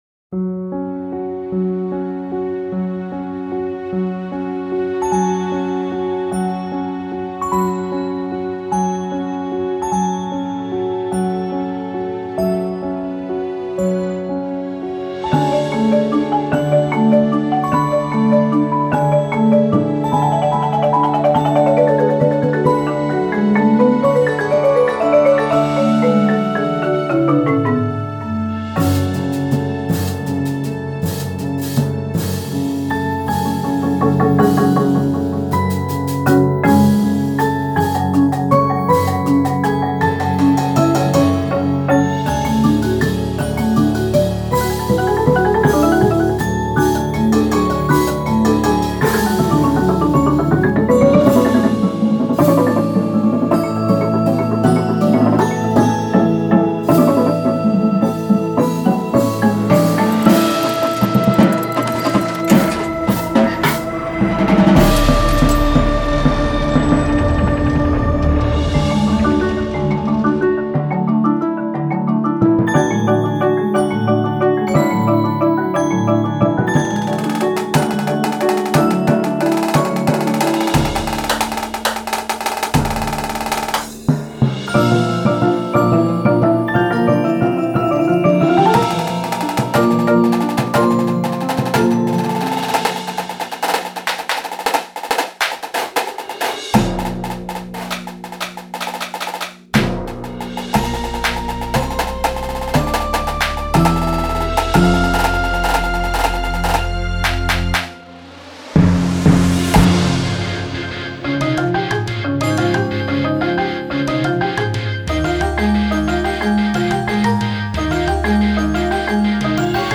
Indoor Percussion Shows
• Snares
Front Ensemble
• 3/4 Vibes
• 2 Synths
• Timpani